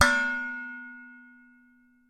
描述：Plastic pot with the lid falling down. Recorded with Samson C01.
标签： plastic pot lid
声道立体声